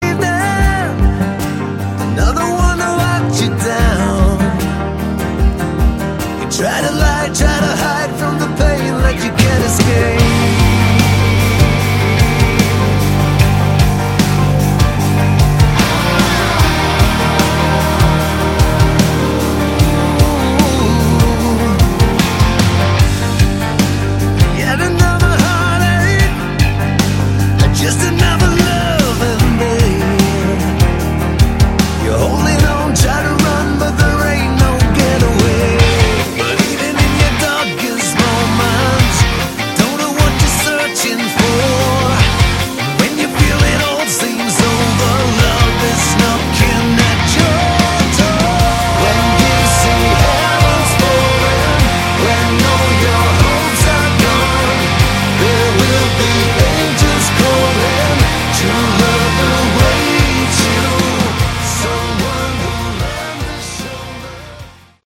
Category: Melodic Hard Rock
lead and background vocals
lead guitar, bass, keyboards, background vocals
drums